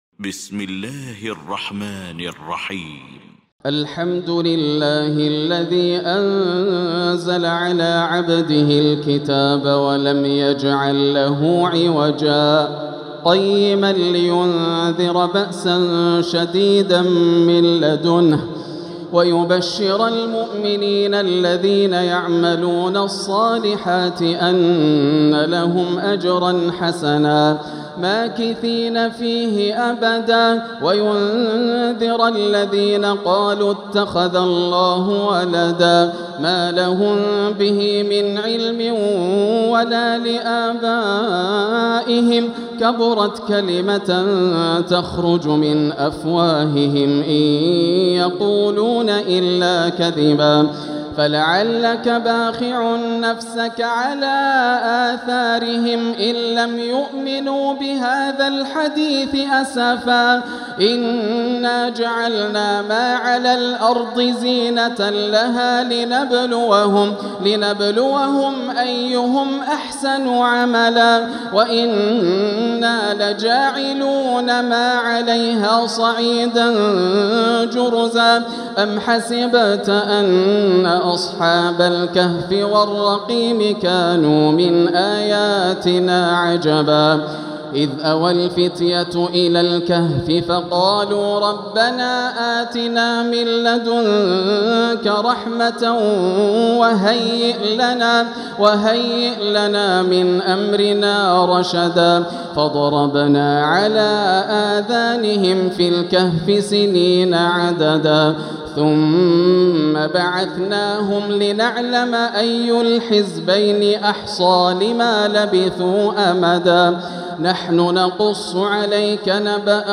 المكان: المسجد الحرام الشيخ: فضيلة الشيخ عبدالله الجهني فضيلة الشيخ عبدالله الجهني فضيلة الشيخ ماهر المعيقلي فضيلة الشيخ ياسر الدوسري الكهف